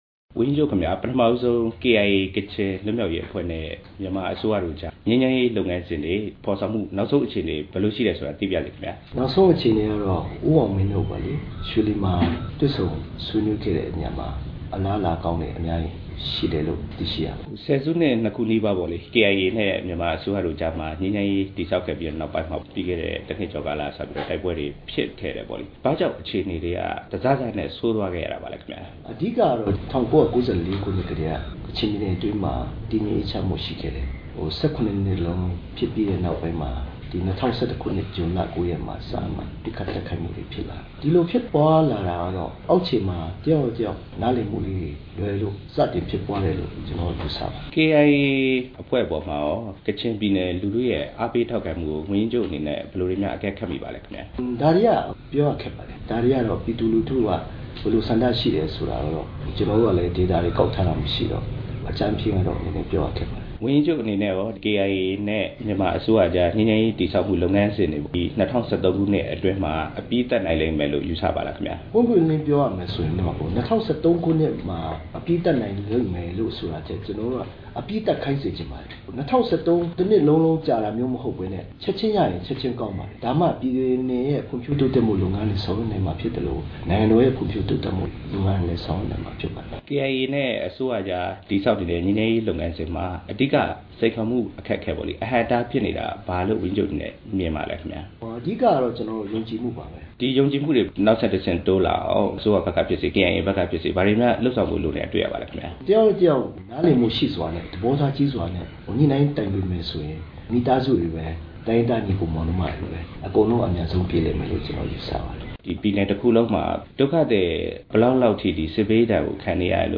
ကချင်ပြည်နယ် ဝန်ကြီးချုပ် ဦးလဂျွန်ငန်ဆိုင်းနှင့် တွေ့ဆုံမေးမြန်းချက်